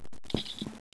Commbadge